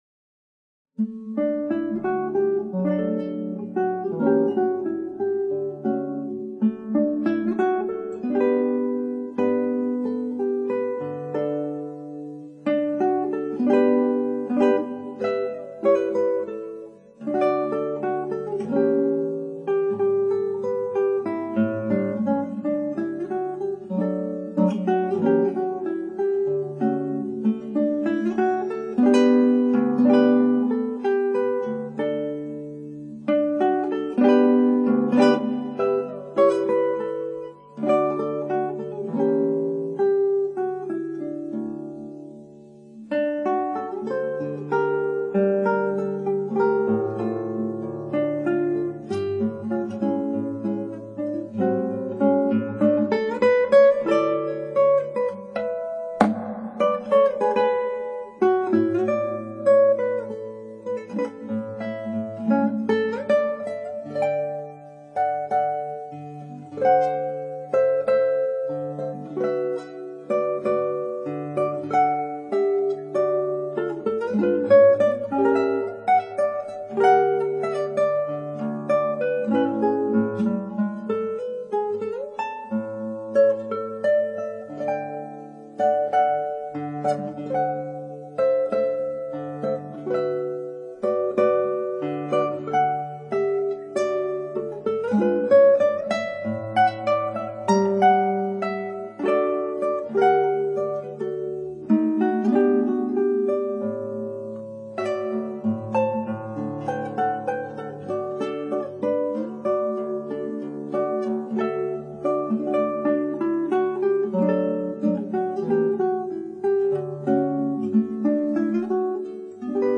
クラシックギター　ストリーミング　コンサート
う～ん、ミスはいっぱいあるし、アレンジはサブイし表情づけは推考されてないし～
間抜けな演奏だワ。